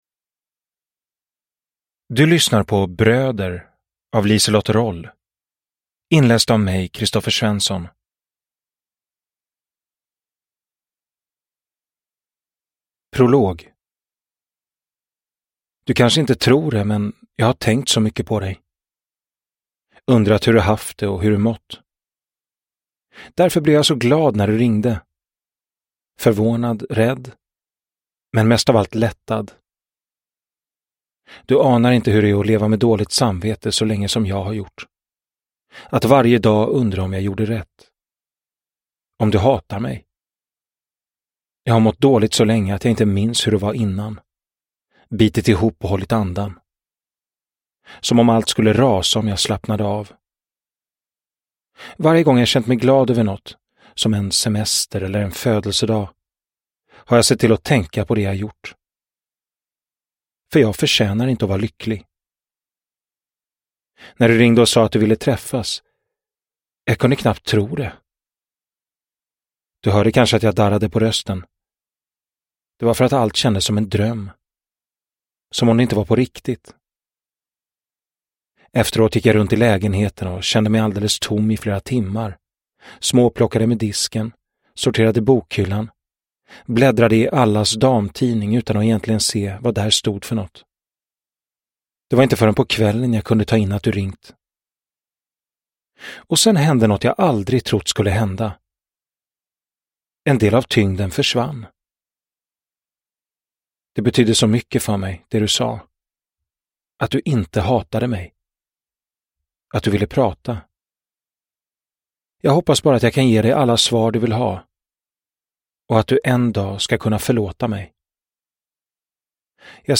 Bröder – Ljudbok – Laddas ner